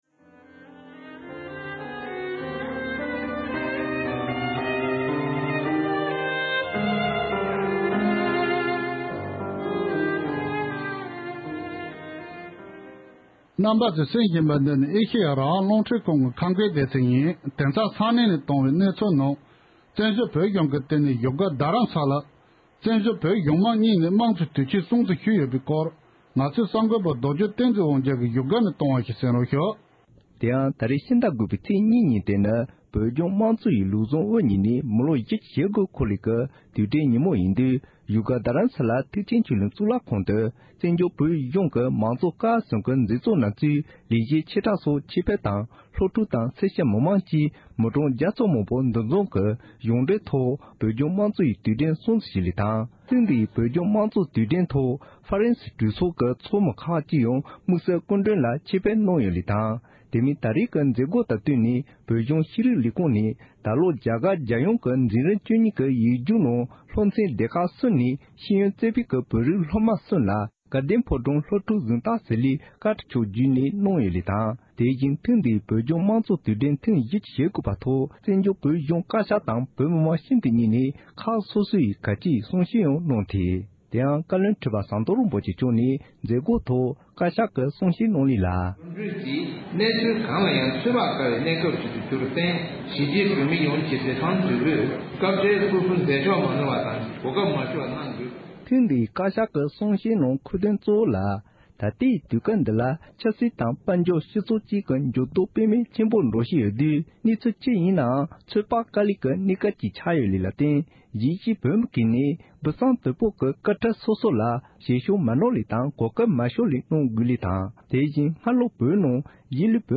མང་གཙོ་ལམ་ལུཊ་དབུ་བརྙེས་ནས་མི་ལོ་༤༩འཁོར་བ། བཙན་བྱོལ་བོད་གཞུང་བཀའ་བློན་ཁྲི་པ་མཆོག་གིས་མང་གཙོ་དུས་དྲན་ཐེངས་བཞི་ཅུ་ཞེ་དགུ་པའི་མཛད་སྒོའི་ཐོག་བཀའ་སློབ་གནང་བཞིན་པ།
སྒྲ་ལྡན་གསར་འགྱུར། སྒྲ་ཕབ་ལེན།